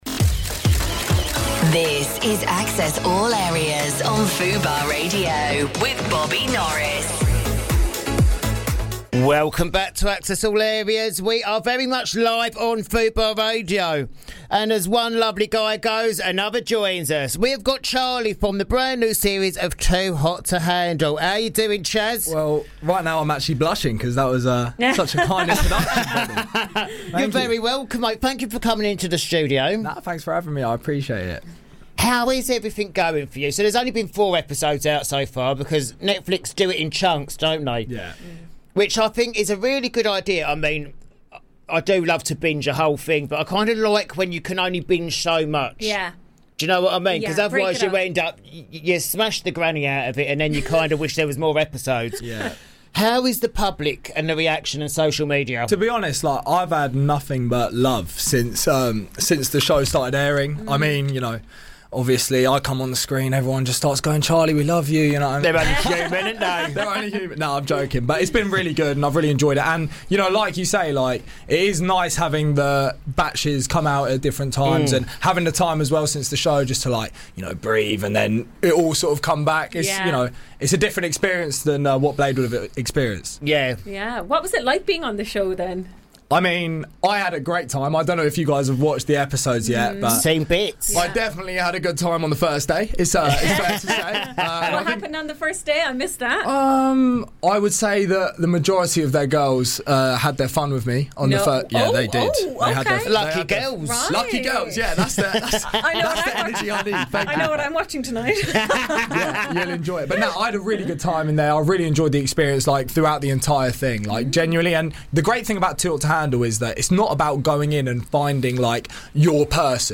This week on Access All Areas, we bring you some of our favourite interviews from the past year! Featured in this episode are Too Hot to Handle bad boy Charlie Jeer, Love Island legend Antigoni Buxton and the cast of the first ever season of Love is Blind UK!